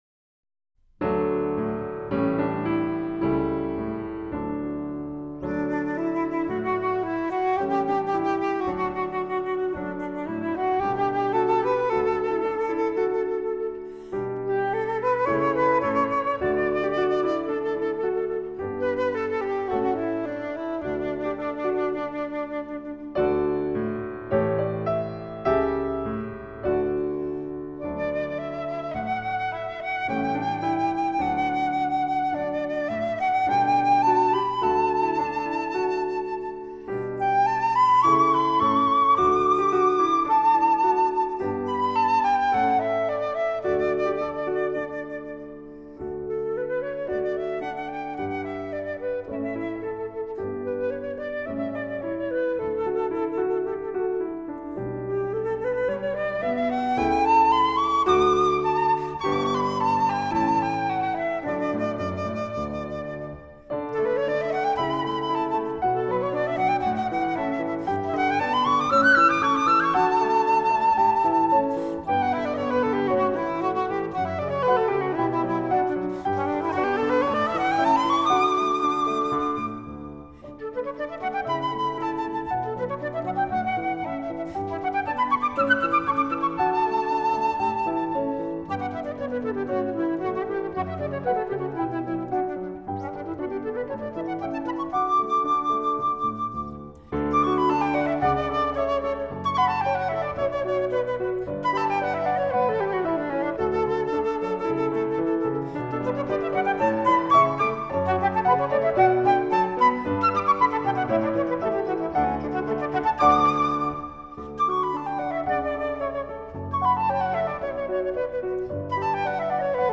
Obsazení: Flöte und Klavier